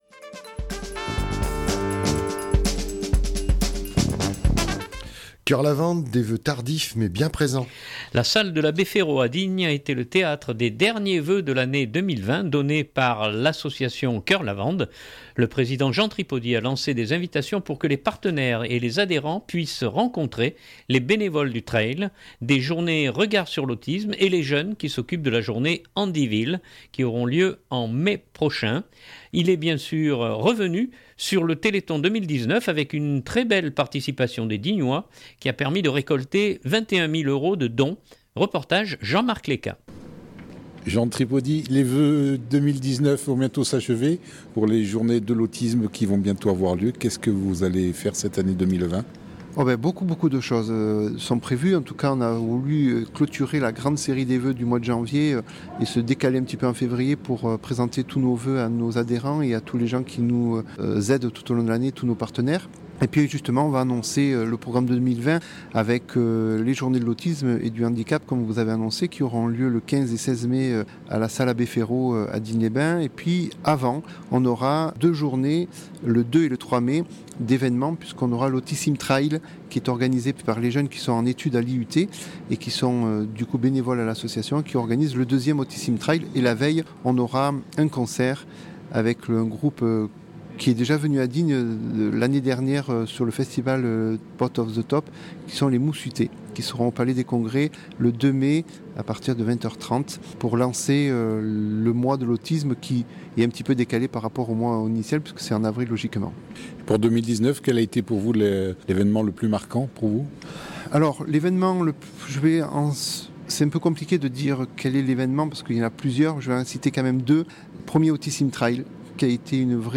La salle de l’Abbé Féraud à Digne a été le théâtre des derniers vœux de l’année 2020 donnés par l’association Cœur lavande.